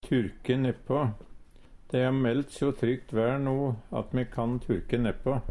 DIALEKTORD PÅ NORMERT NORSK turke nepå tørke gras/høy på bakken Eksempel på bruk Dei har meldt so trygt ver no, at me kann turke nepå.